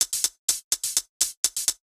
Index of /musicradar/ultimate-hihat-samples/125bpm
UHH_ElectroHatA_125-04.wav